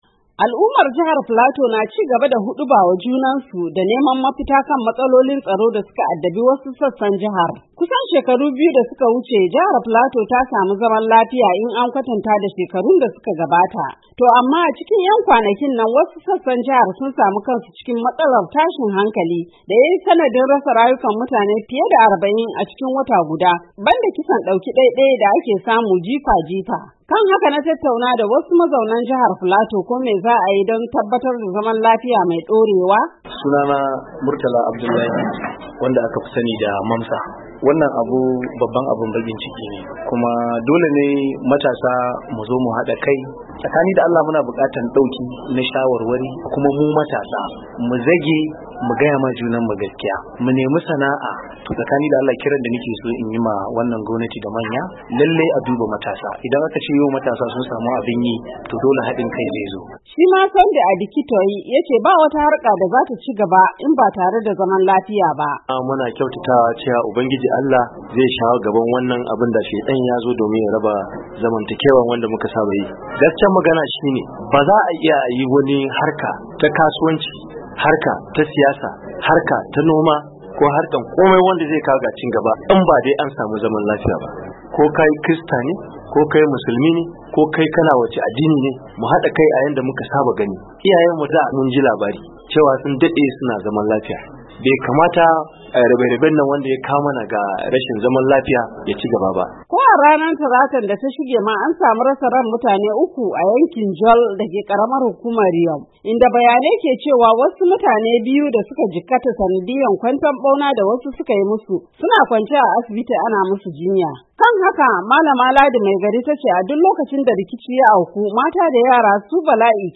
Matasan jihar sun bayyana ra’ayoyinsu akan yadda suke gani za a fitowa lamarin.